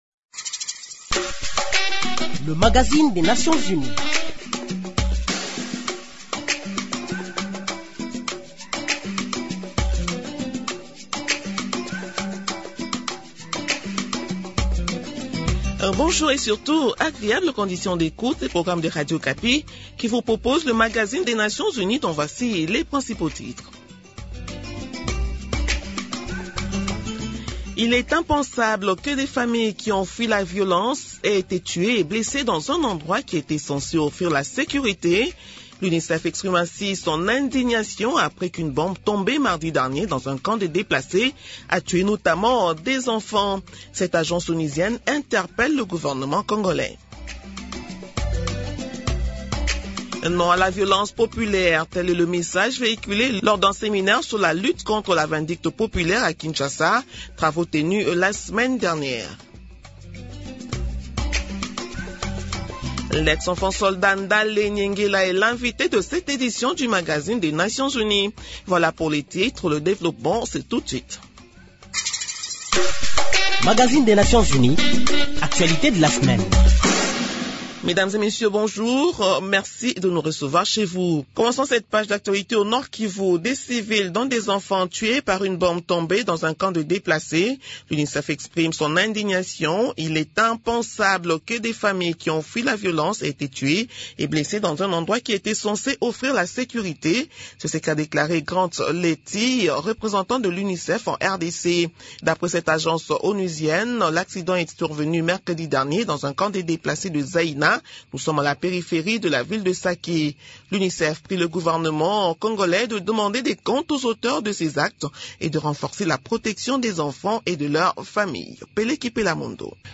Le magazine des Nations unies reçoit dans ce premier entretien l